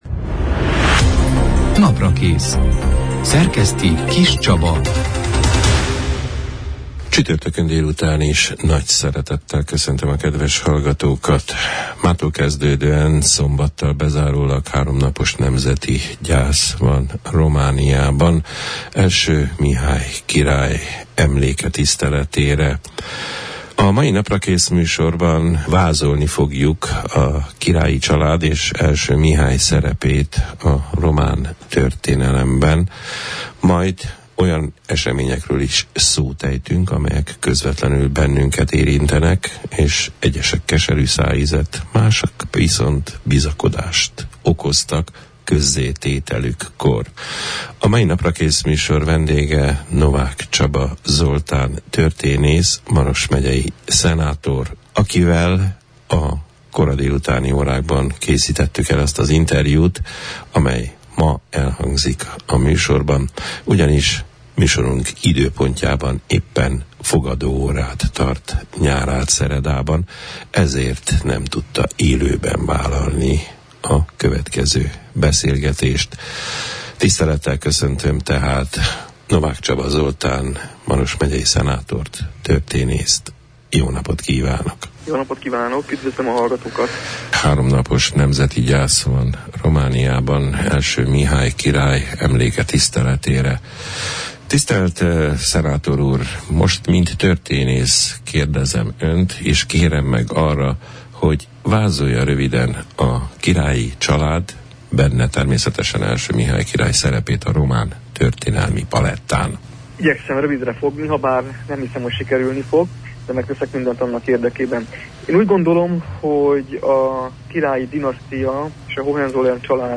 Az I. Mihály király emlékére meghírdetett három napos nemzeti gyász kapcsán a király és családja szerepéről a román történelemben, a csíksomlyói búcsú Unesco listára való felterjesztese ügyében történt újabb eseményekről, az oktatás területén felmerült kérdéses és biztató eseményekről, a gyakornoki pályázata eredményéről beszélgettünk a december 14 -én, csütörtökön elhangzott Naprakész műsorban Novák Csaba Zoltán történésszel, Maros megyei szenátorral.